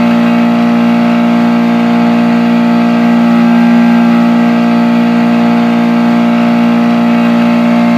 formulaford.wav